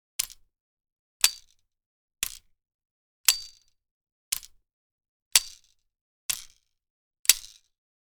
Rotating Sprinkler Loop, Garden, Outdoor Sound Effect Download | Gfx Sounds
Rotating-sprinkler-loop-garden-outdoor.mp3